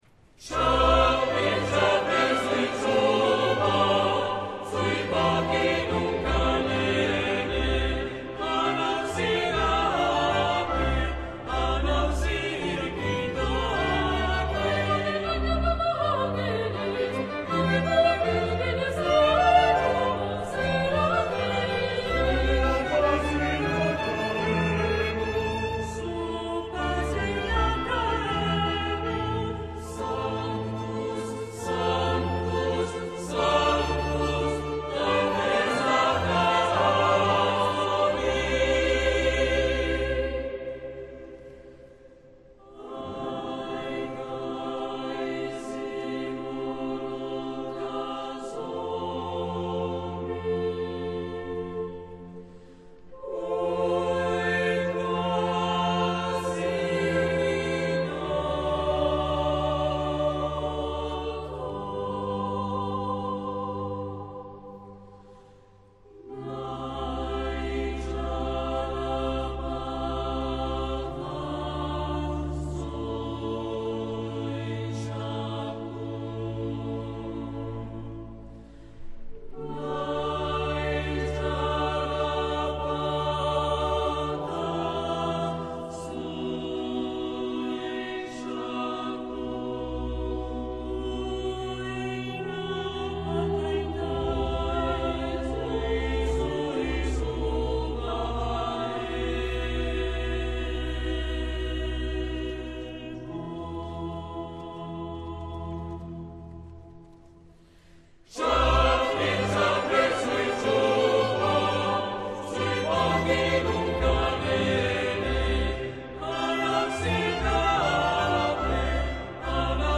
Barocke Jesuitenmusik